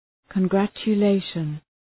Προφορά
{kən,grætʃə’leıʃən}